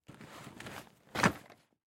Звуки кувырка
Звук перекатывания человека через тело